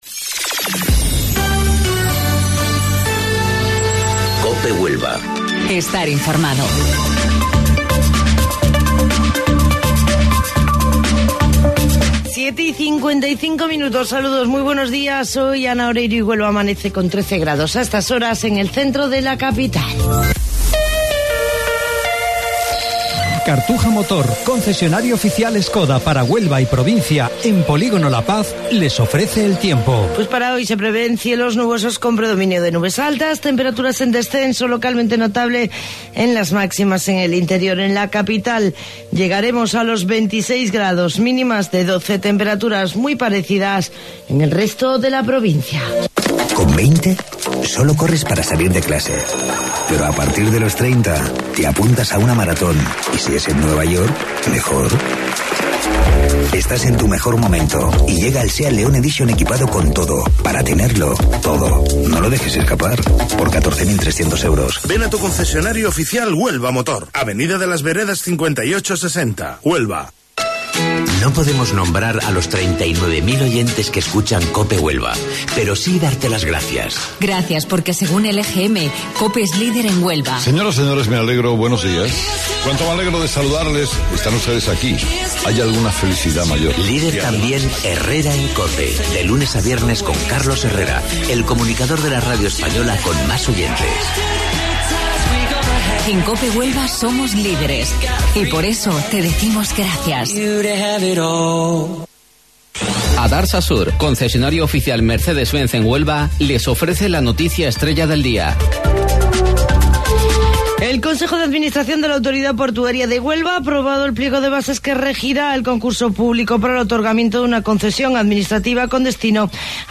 AUDIO: Informativo Local 07:55 del 17 de Mayo